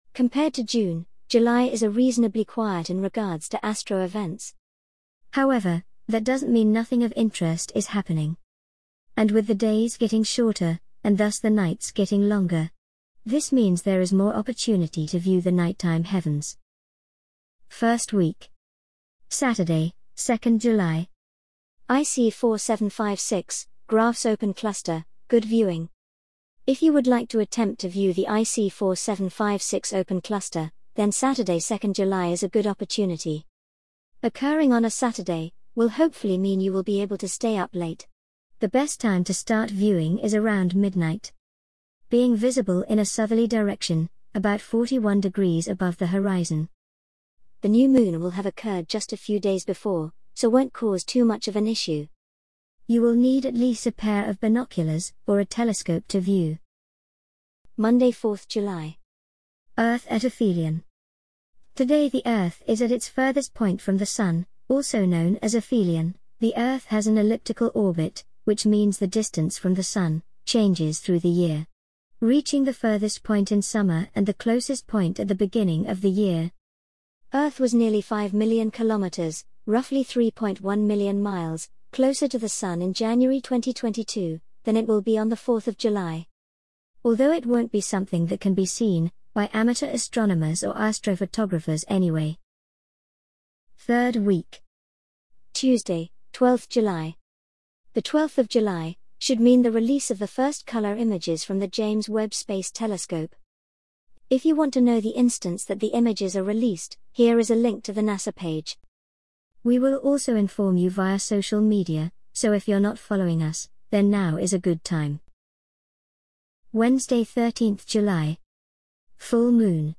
An audio reading of the Realm of Darkness July 2022 Article